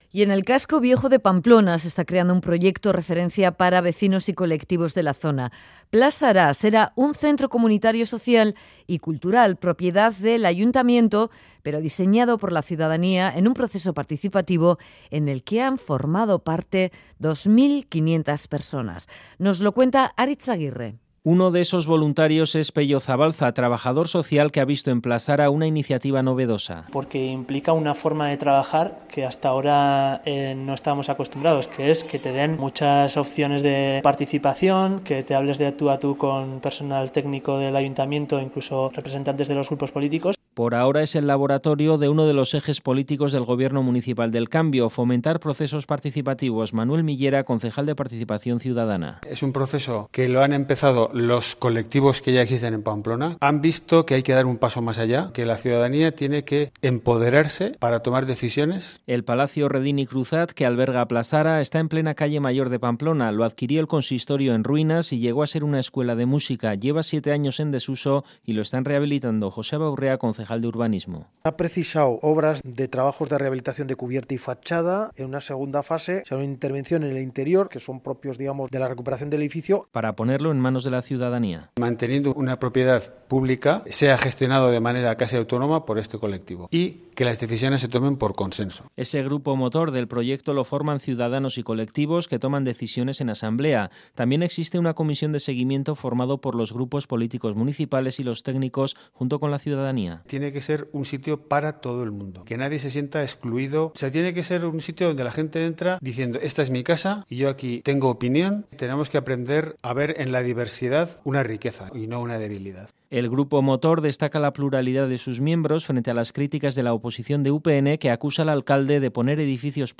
Radio Euskadi REPORTAJES Plazara!